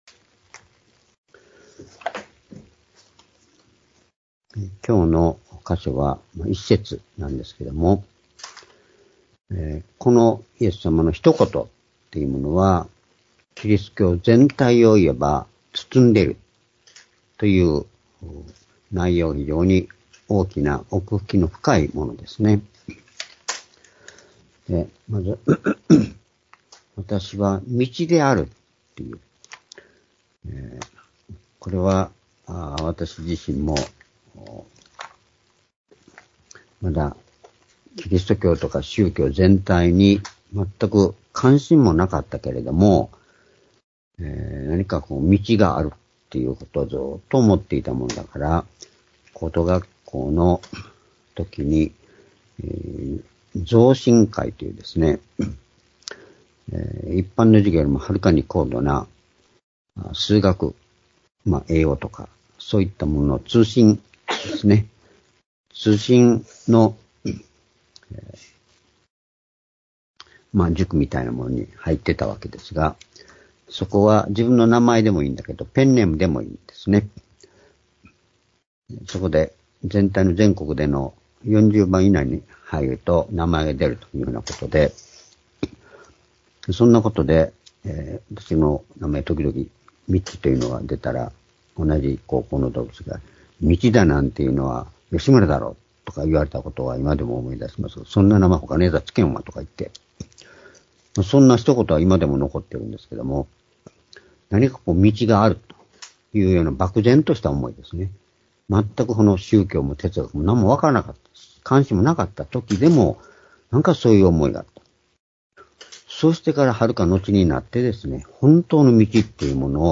「私は道であり、真理であり 命である」-ヨハネ１４の６－２０２４年2月25日（主日礼拝）